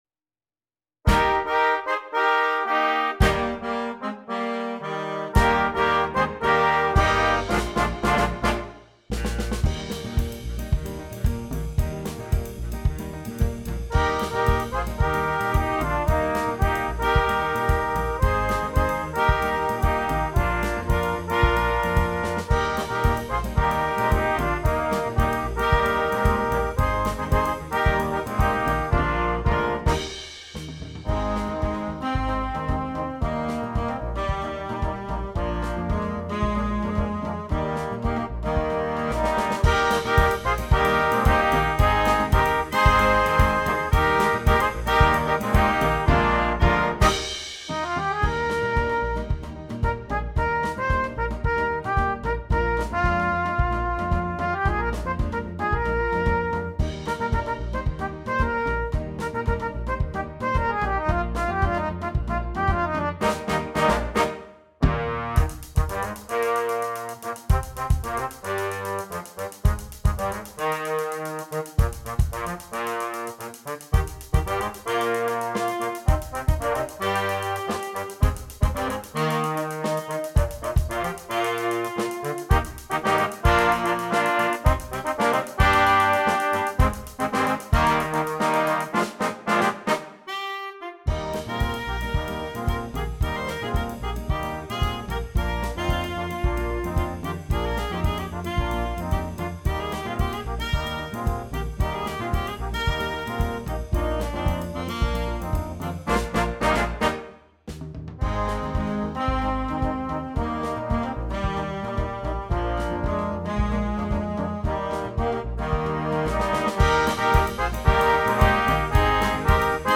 Jazz Band
catchy, latin flavoured version
There is a written out solo included for Trumpet.